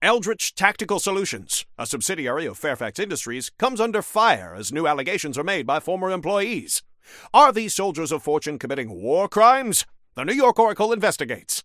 Newscaster_headline_65.mp3